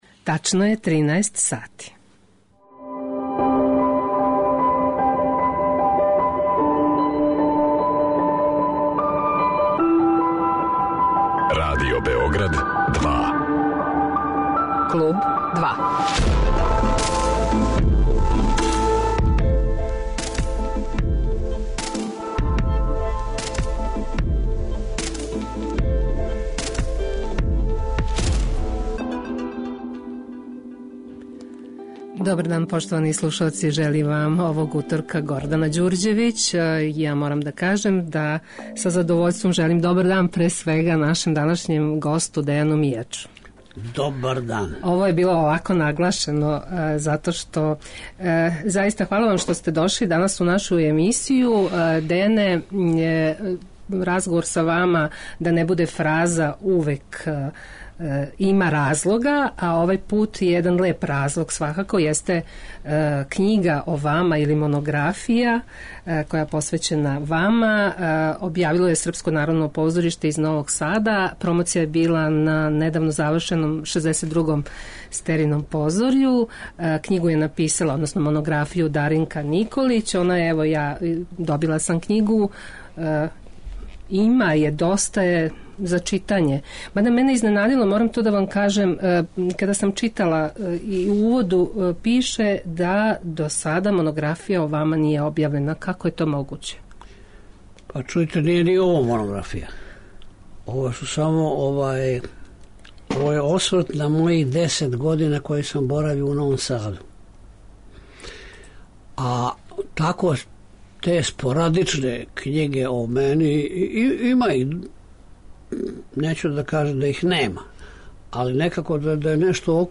Гост 'Клуба 2' је Дејан Мијач, један од најзначајнијих српских позоришних редитеља .